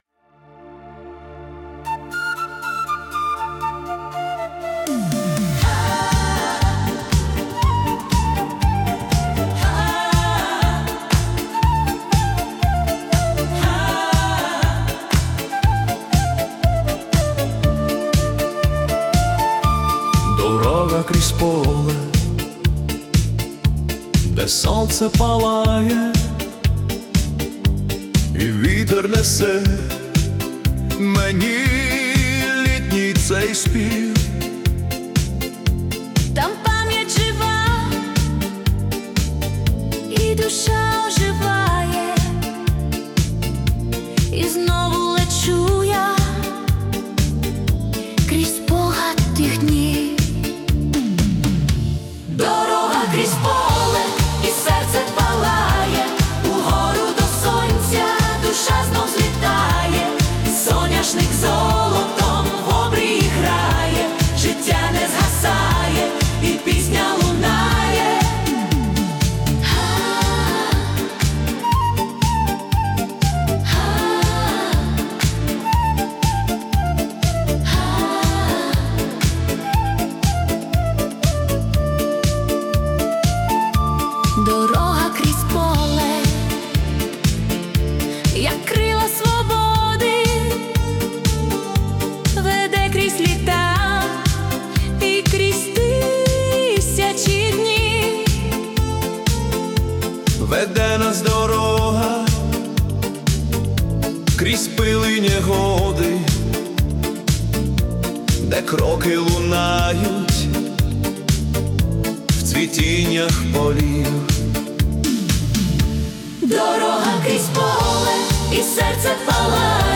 🎵 Жанр: Italo Disco / Nostalgia
це сонячна та ностальгійна пісня
у стилі Italo Disco (120 BPM)